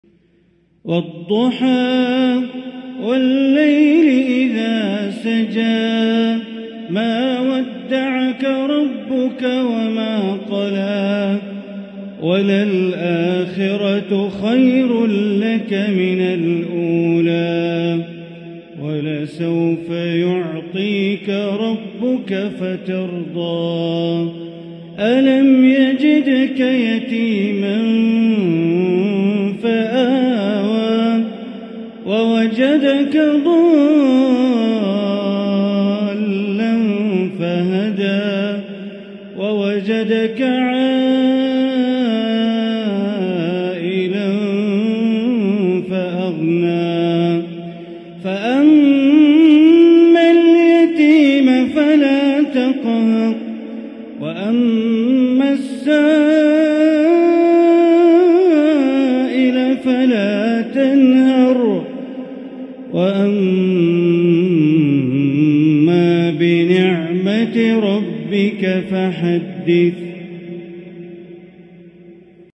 سورة الضحى > مصحف الحرم المكي > المصحف - تلاوات بندر بليلة